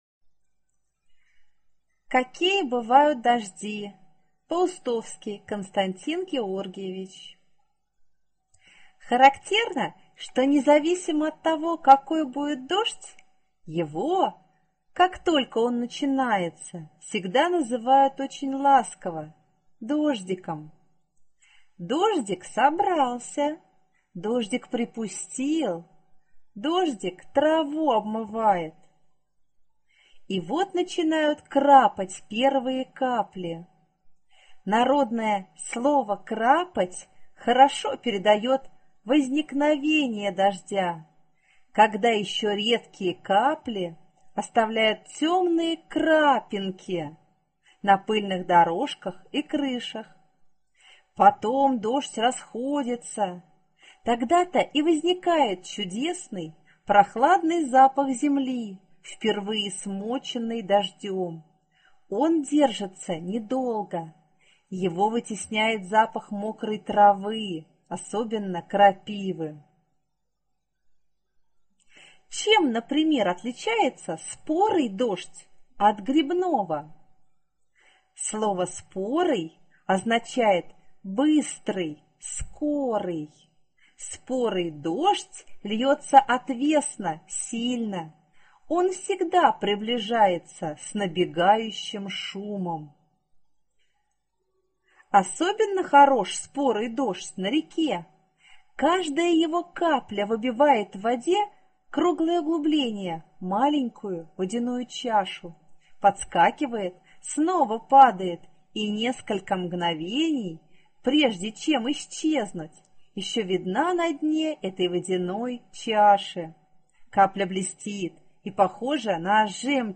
Аудиорассказ «Какие бывают дожди»